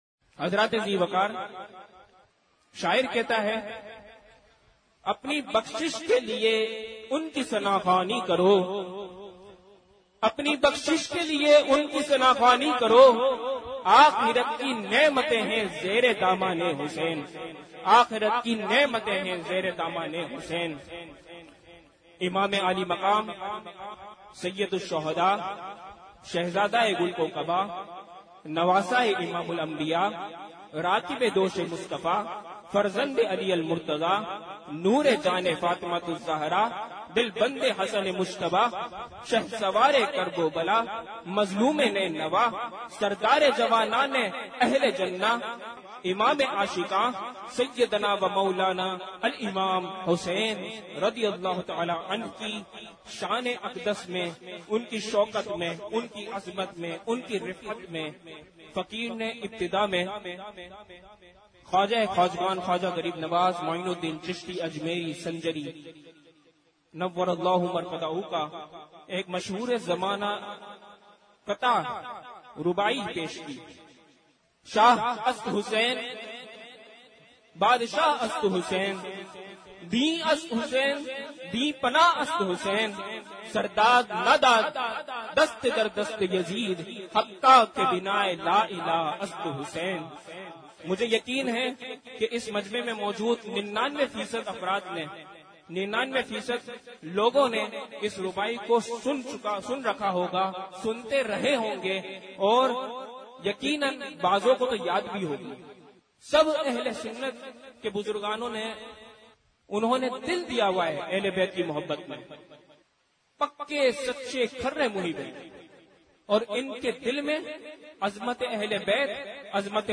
بیانات